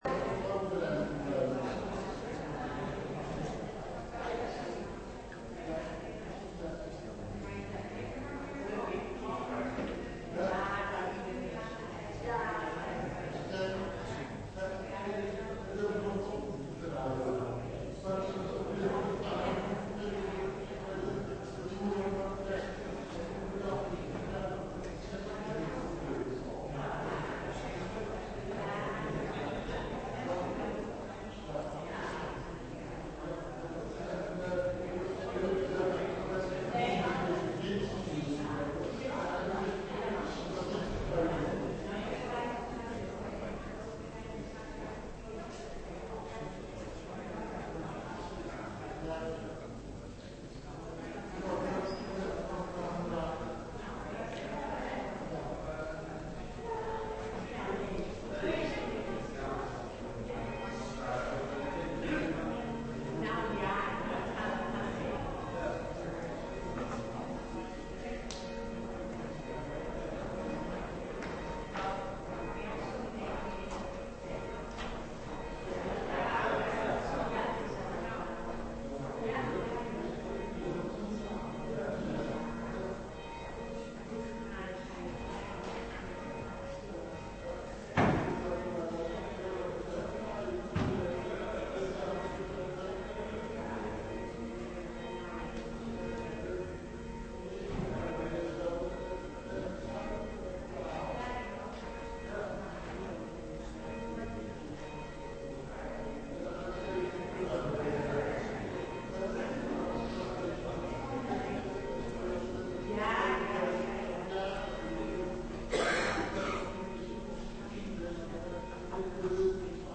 25 december 2015 kerstochtend: Filippenzen 2:5-6 - Pauluskerk Gouda